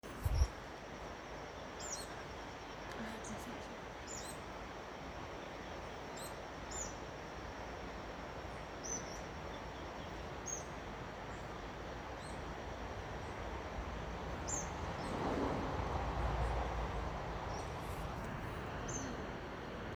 Coludito Canela (Leptasthenura fuliginiceps)
Nombre en inglés: Brown-capped Tit-Spinetail
Localización detallada: Valle Grande
Condición: Silvestre
Certeza: Fotografiada, Vocalización Grabada
Coludito-canela_1.mp3